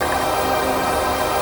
RAVEPAD 04-LR.wav